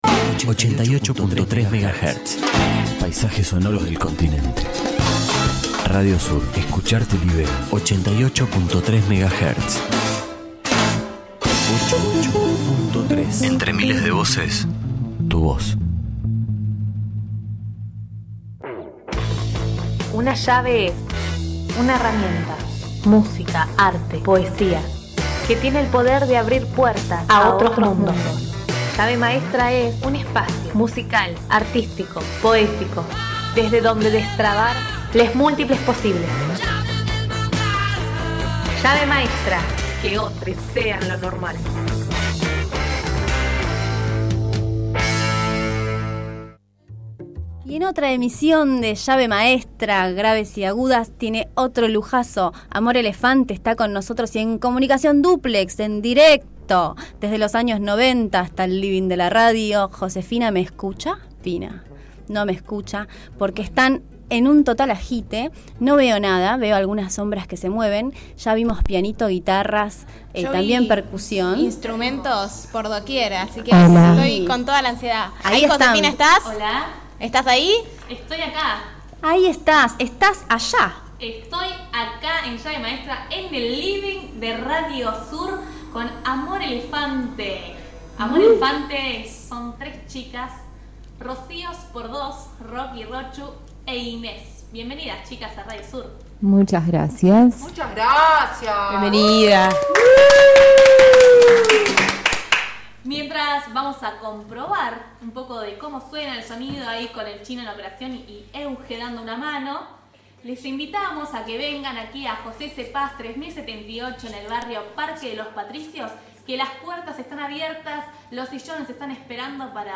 teclados y voz
guitarra y voz